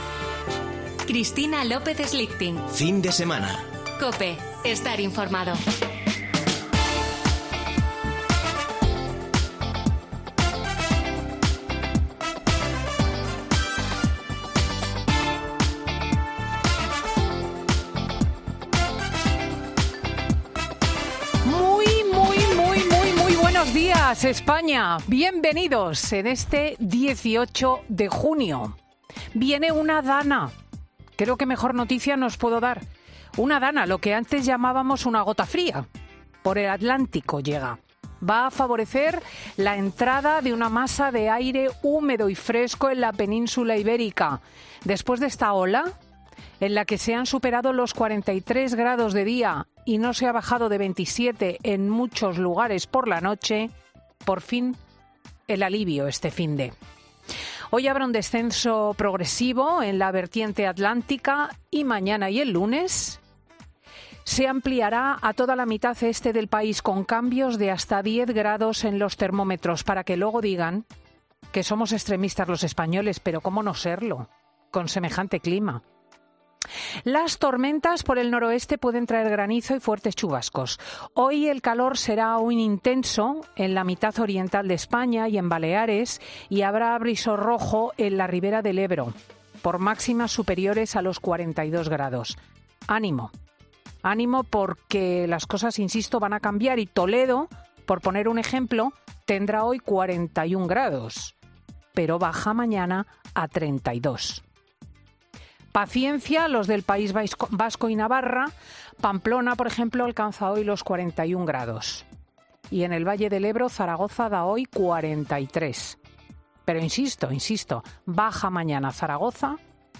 Ya puedes escuchar el monólogo de Cristina López Schlichting en Fin de Semana COPE